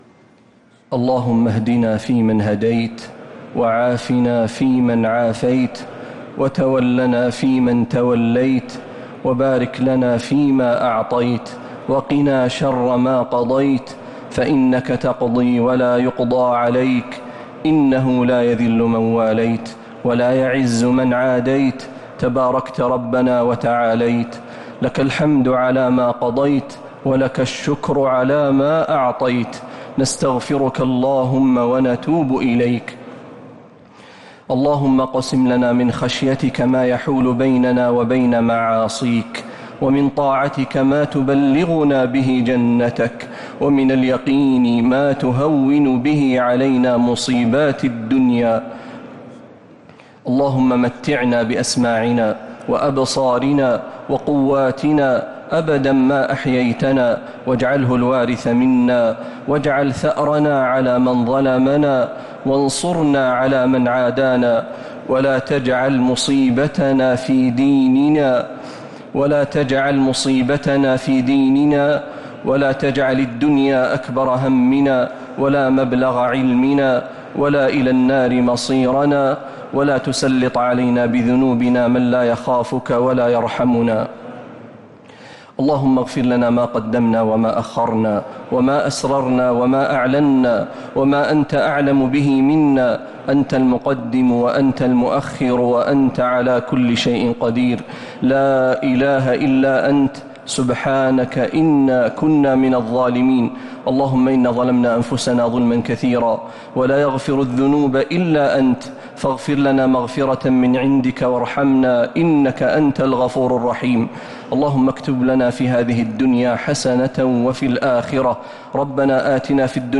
دعاء القنوت ليلة 13 رمضان 1447هـ | Dua 13th night Ramadan 1447H > تراويح الحرم النبوي عام 1447 🕌 > التراويح - تلاوات الحرمين